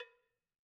Cowbell1_Hit_v1_rr1_Sum.wav